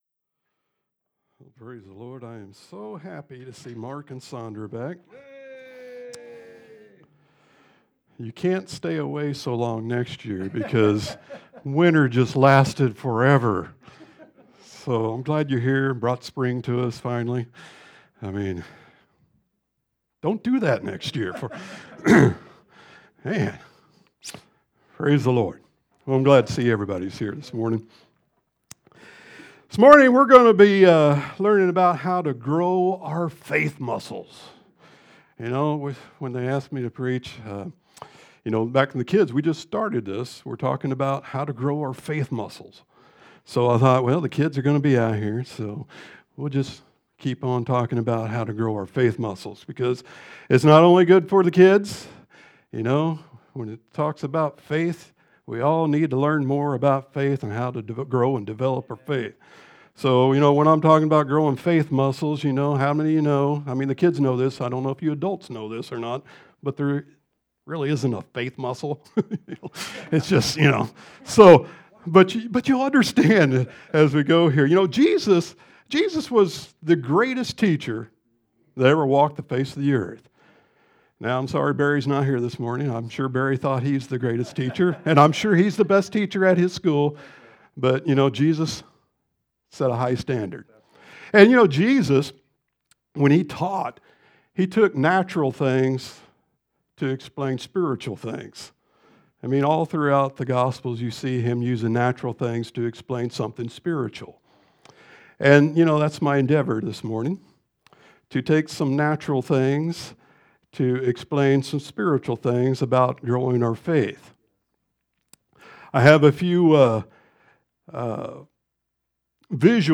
A collection of sermons/pastoral messages from 2018-2022.